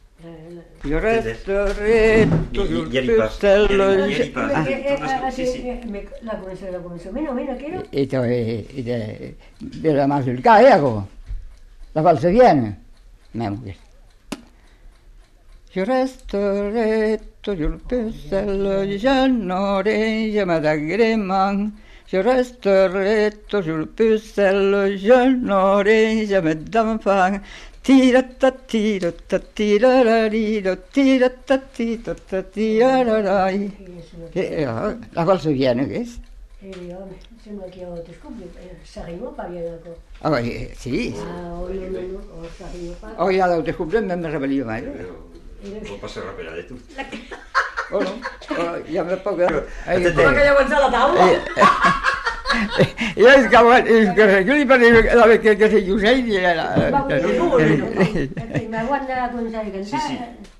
Lieu : Moncrabeau
Genre : chant
Effectif : 1
Type de voix : voix d'homme
Production du son : chanté ; fredonné
Danse : mazurka